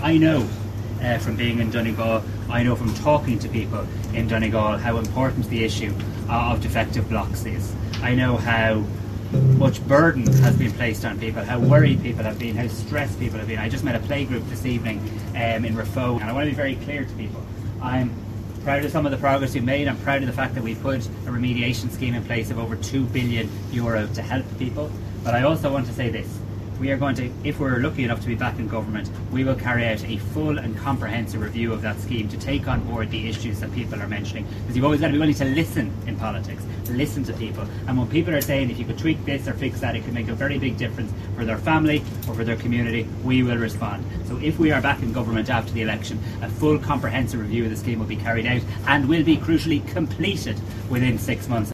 Speaking in Ballybofey last night, the Taoiseach says he will listen to the issues affecting defective block homeowners: